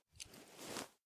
lighter_holster.ogg